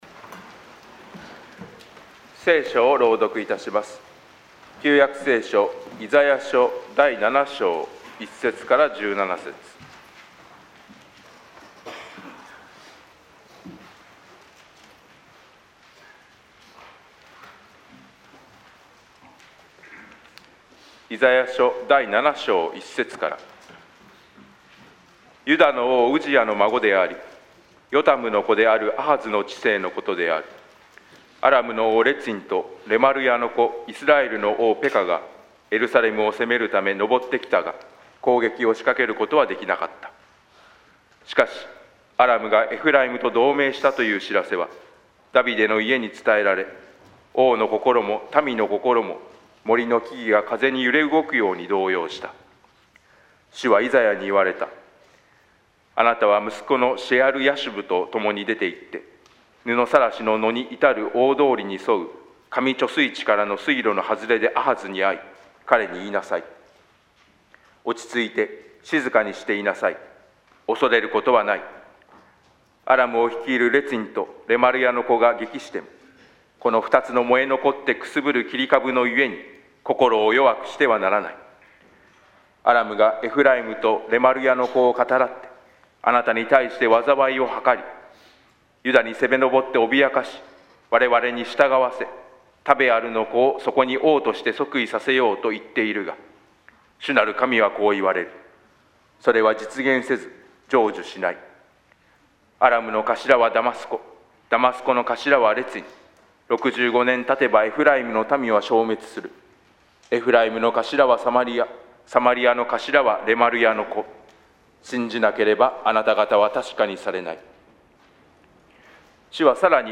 【クリスマス礼拝】神は我々と共におられる - 横浜指路教会